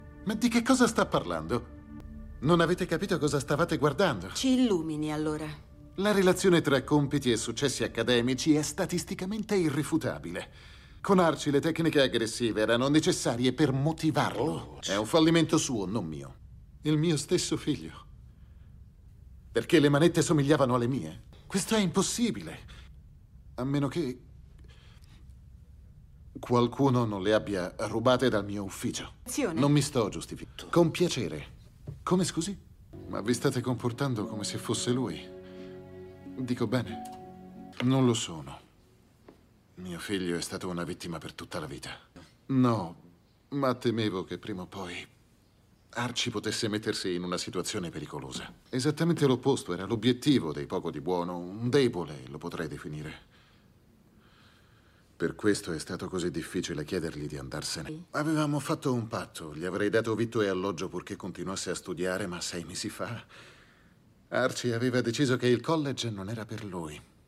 nel telefilm "The Mentalist", in cui doppia William R. Moses.